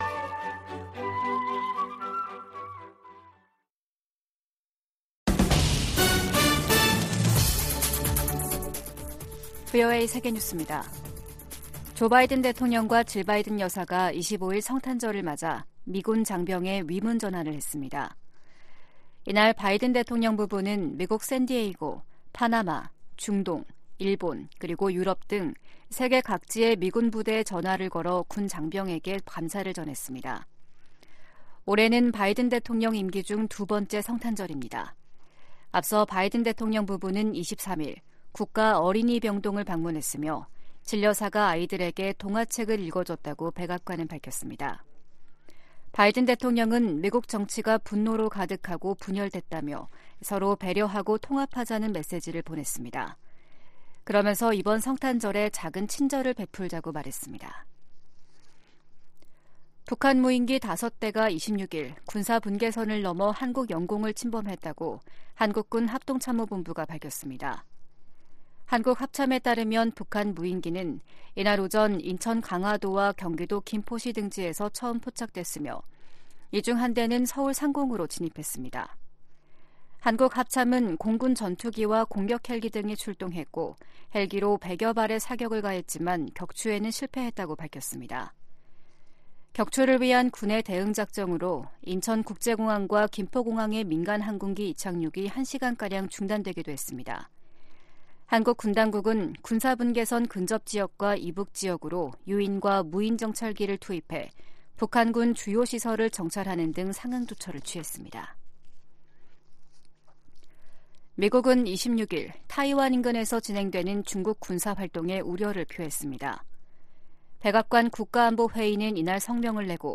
VOA 한국어 아침 뉴스 프로그램 '워싱턴 뉴스 광장', 2022년 12월 27일 방송입니다. 블라디미르 푸틴 러시아 대통령이 우크라이나 전쟁과 관련한 협상에 열려있다고 밝혔습니다. 미국 국무부가 북한 정권의 단거리탄도미사일 발사를 규탄하면서 이번 발사가 유엔 안보리 결의에 위배된다고 지적했습니다.